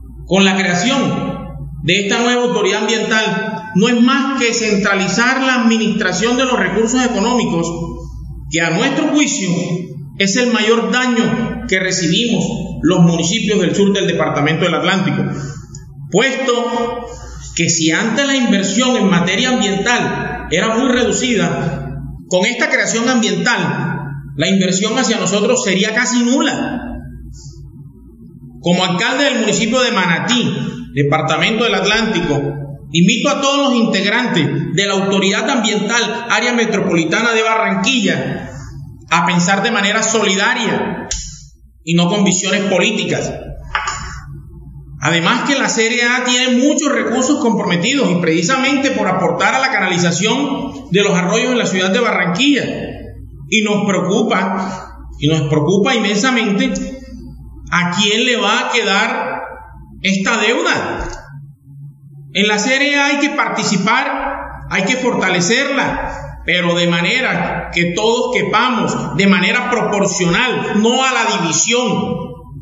VOZ-ALCALDE-MANATI.mp3